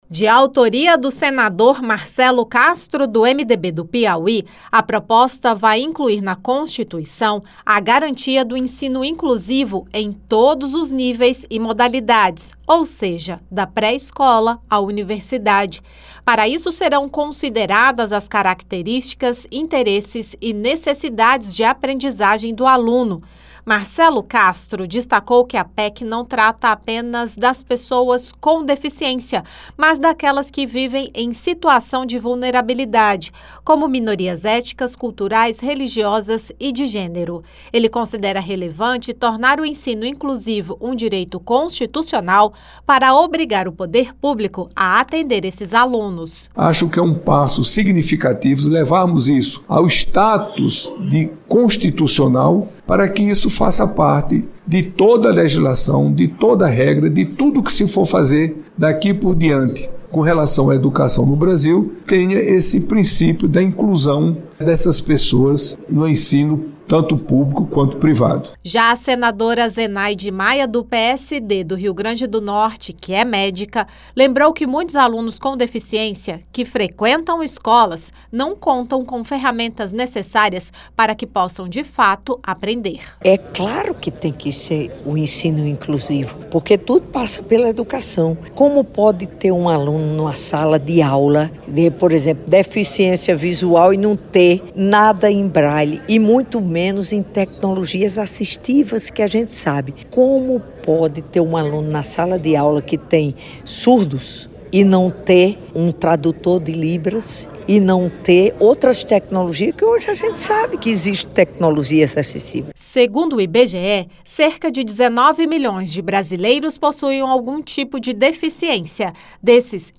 O autor, senador Marcelo Castro (MDB-PI), explicou que a PEC prevê que esse direito não se limite às pessoas com deficiência, mas àquelas que pertencem a grupos vulneráveis, como minorias éticas, culturais, religiosas e de gênero. Já a senadora Zenaide Maia (PSD-RN), que é médica, declarou que essa PEC permitirá que os alunos com deficiência possam de fato contar com assistência e ferramentas que os auxiliem no processo de aprendizagem.